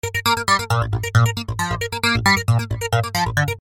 吉他 " CRAZYFLANGE6
描述：吉他，低音，循环
Tag: 低音 循环 吉他